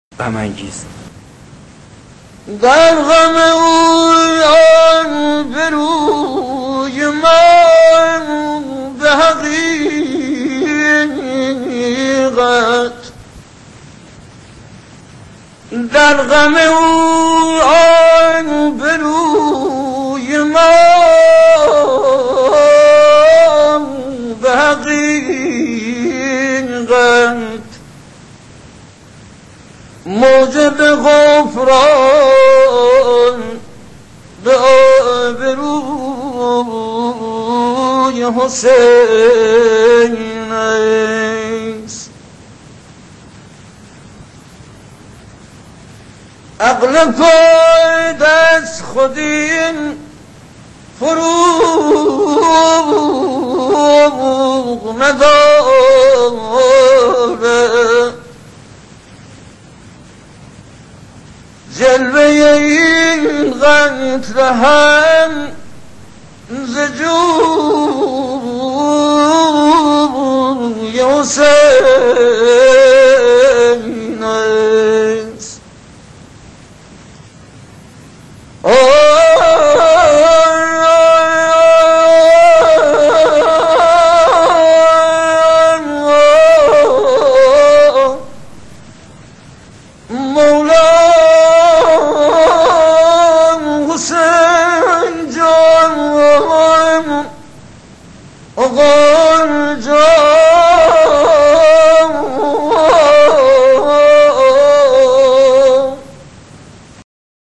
مداحی به روایت دستگاه‌های موسیقی - آرمان هیأت
در ادامه دو قطعه را خواهید شنید که با استفاده از دستگا‌ه‌های موسیقی خوانده شده‌اند. قطعه اول از دستگاه دشتی و گوشه غم انگیز انتخاب شده و قطعه دوم هم از دستگاه همایون و گوشه بیات راجع انتخاب شده است.